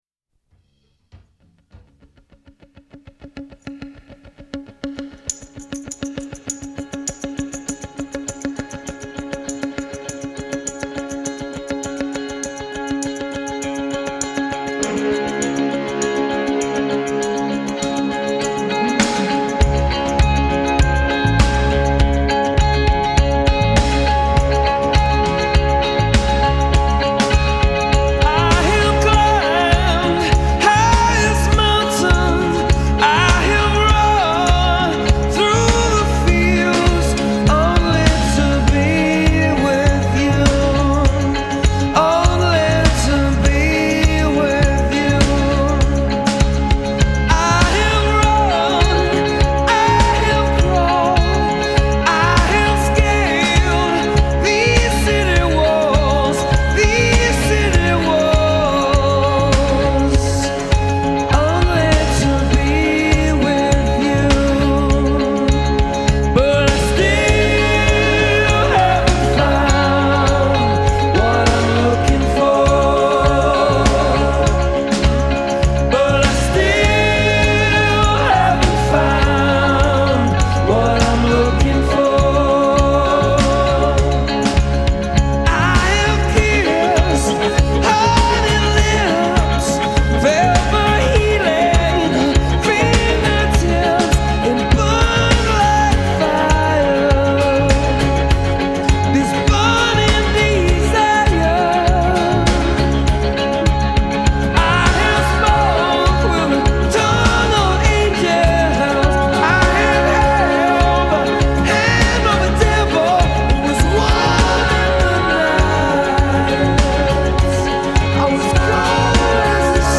Tags1980s 1987 Europe-Asia Ireland Rock